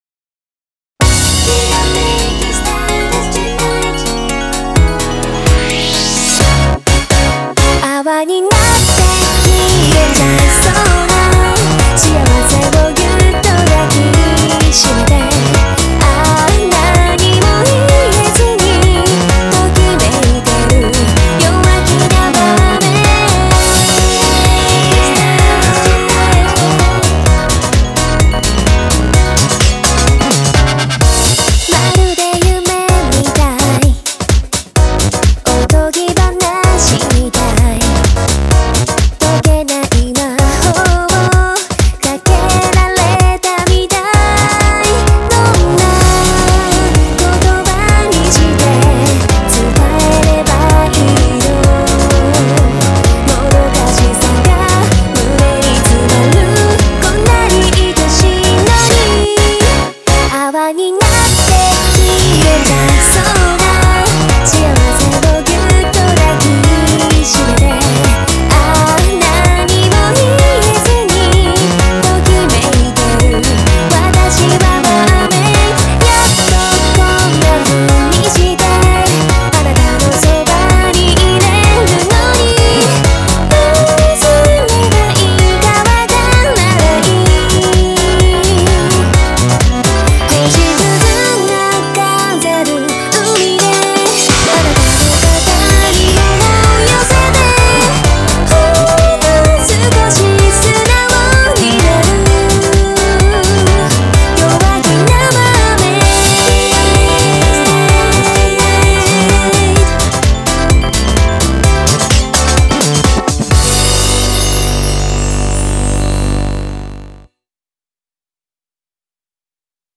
Vocal
BPM128